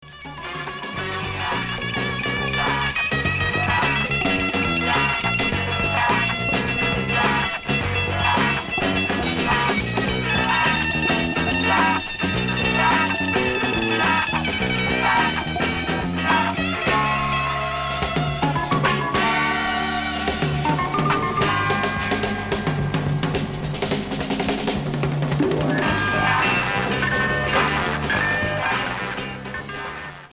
Groove psyché